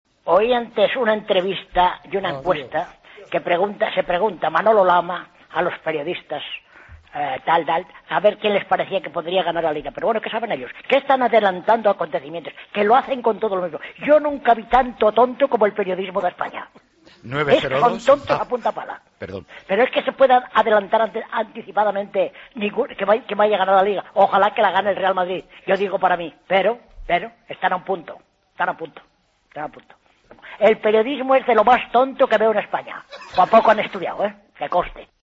AUDIO: Nuestro oyente enfurecido critica la encuesta de Manolo Lama.